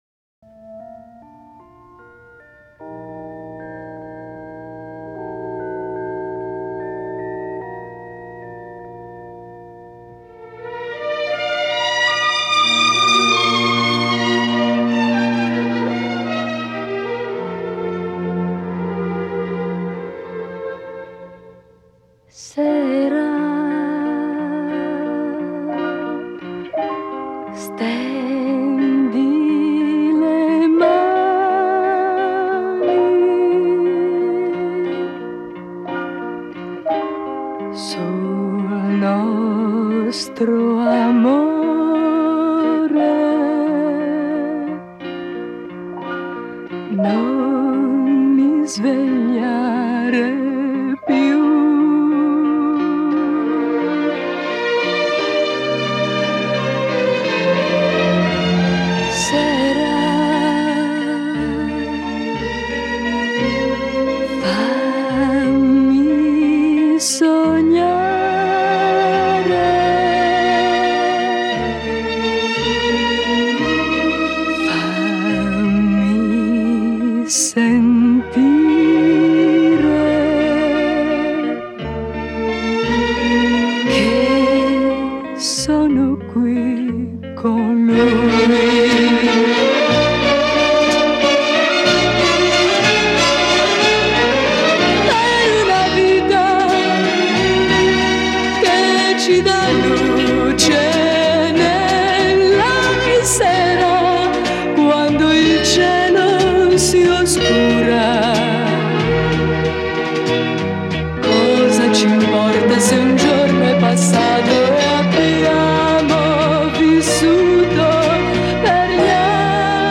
Genre: Pop, oldies